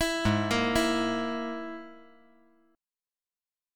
Listen to G#sus2#5 strummed